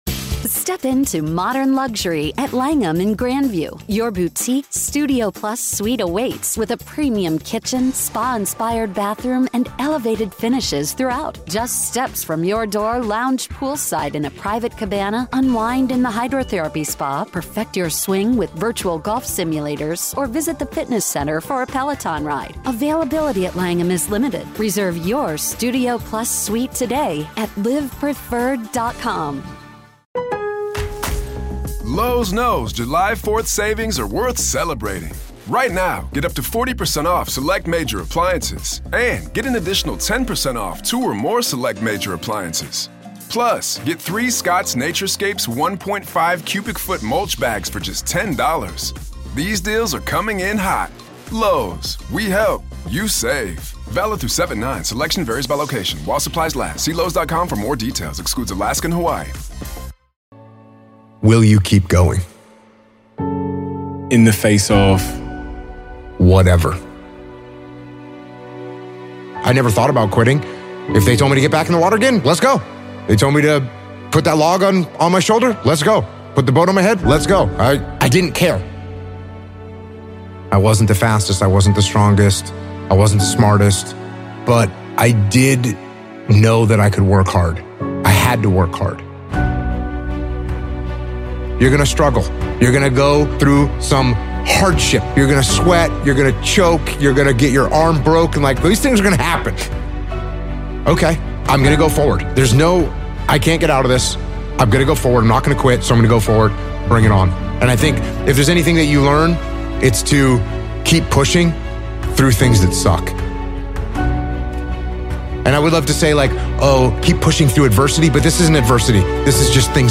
BUILD IMMENSE INNER STRENGTH & DISCIPLINE! One of the Best Motivational Speeches Ever Featuring Jocko Willink.